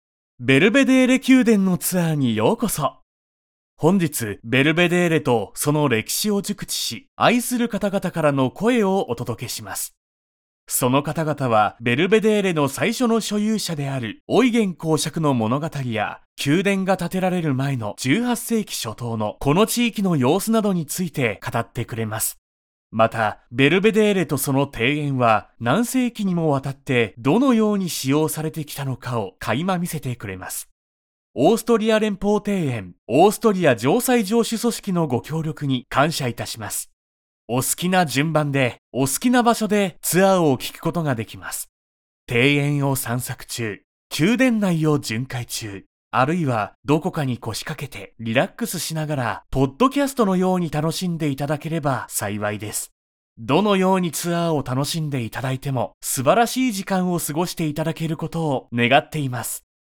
AUDIO DEMOS